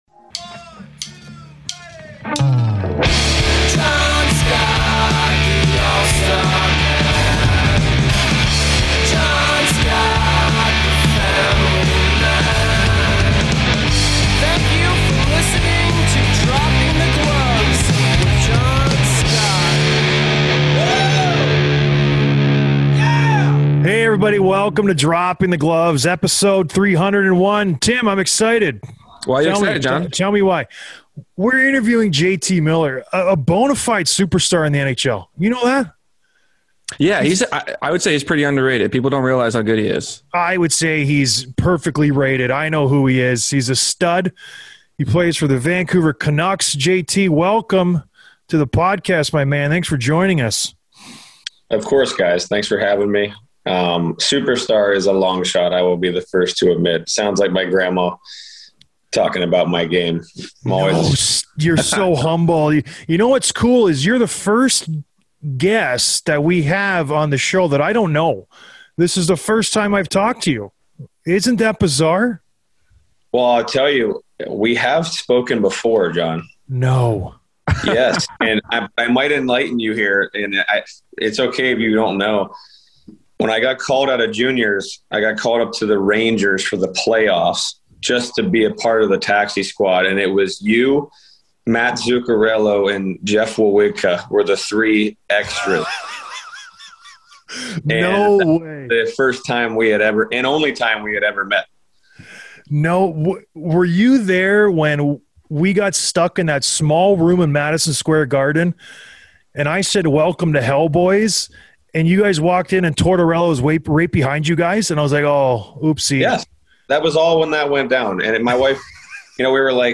Interview with JT Miller, Vancouver Canucks Forward